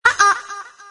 Descarga de Sonidos mp3 Gratis: ohoh 1.
ohoh-.mp3